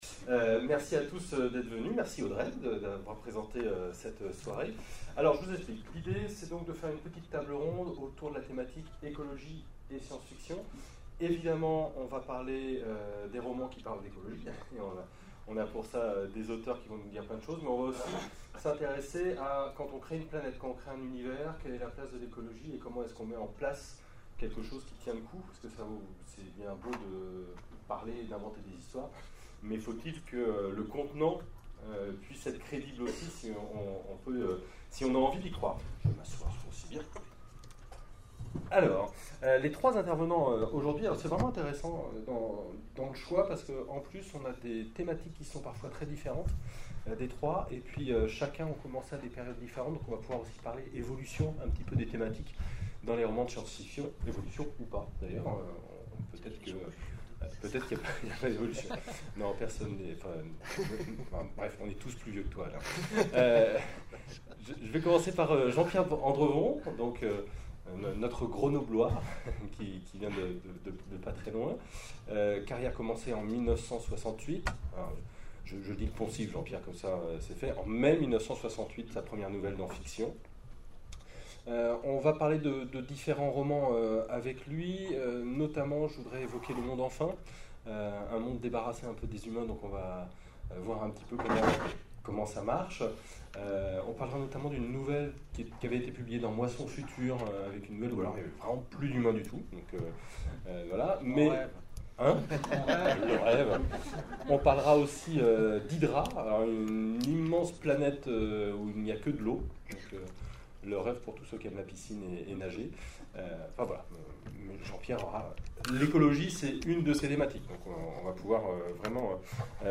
Mots-clés Rencontre avec un auteur Conférence Partager cet article